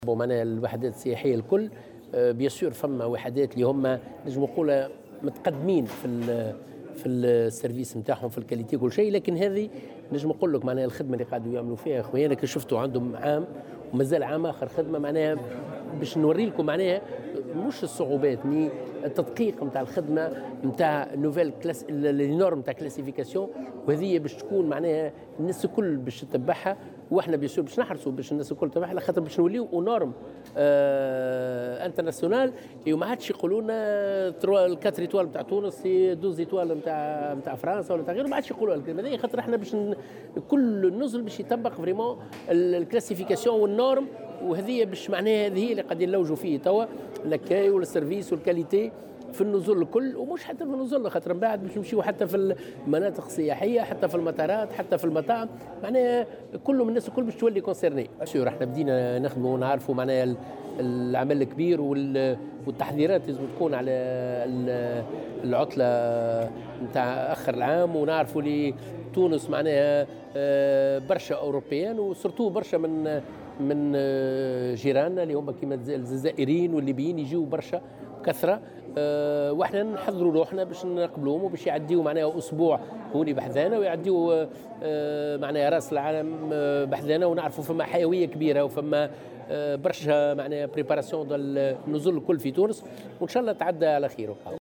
وأضاف الوزير في تصريح لمراسلة "الجوهرة أف أم" أن التحضيرات انطلقت لاحتفالات السنة الادارية الجديدة بمختلف الوحدات الفندقية لاستقبال الزوار من الأسواق الأوروبية والسوقين الجزائرية والليبية كذلك.